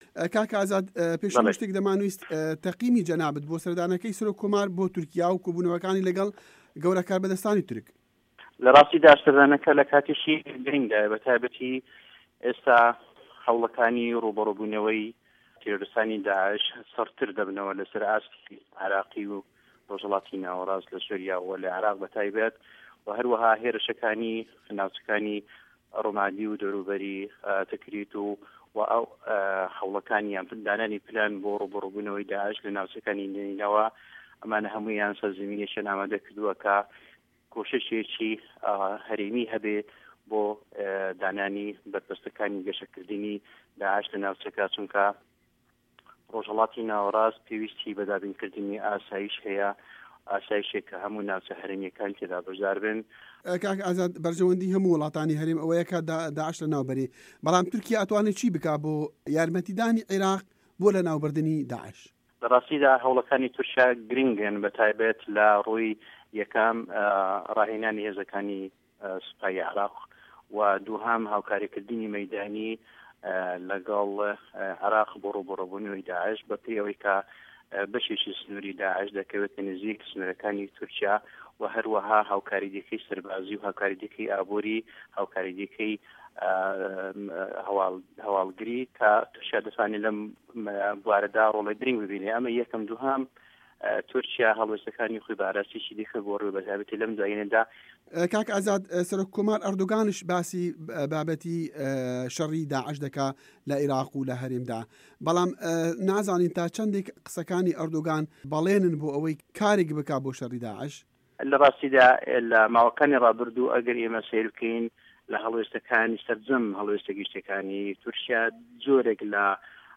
وتووێژی